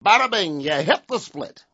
wack_badabinghitsplit.wav